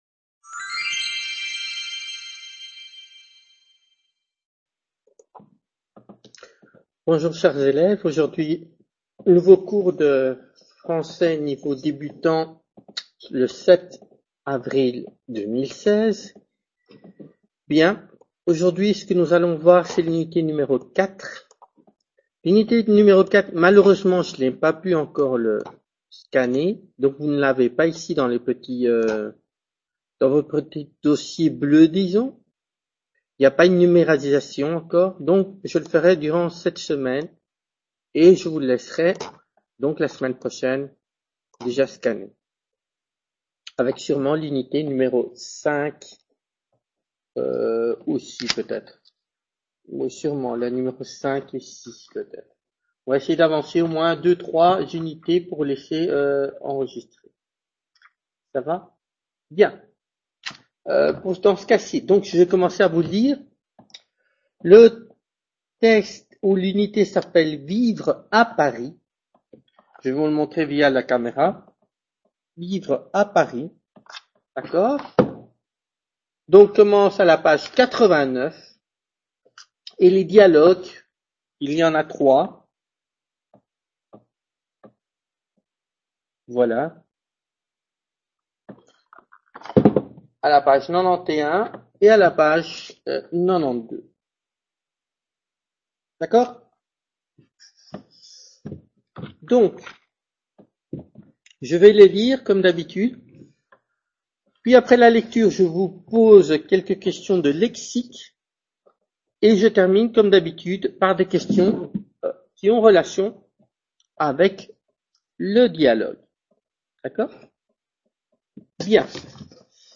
Clase de Francés Nivel Debutante 7/04/2016 | Repositorio Digital